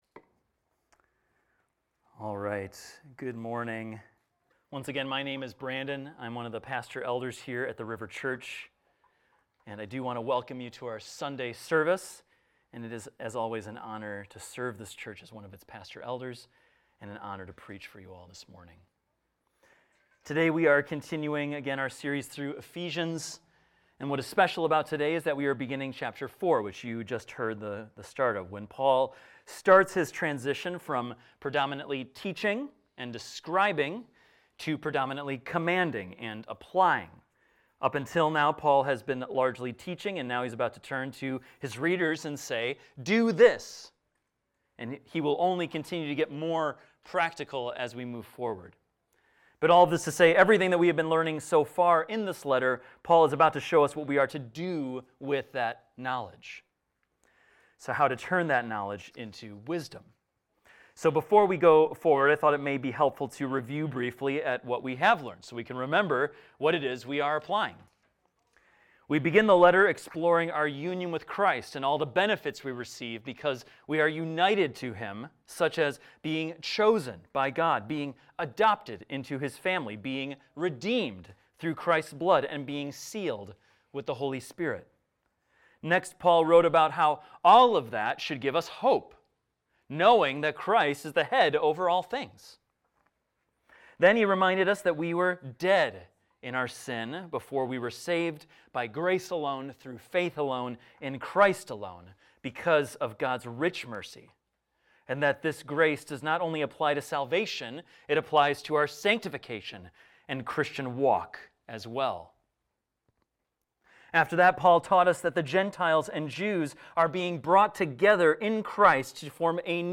This is a recording of a sermon titled, "Walk In Unity."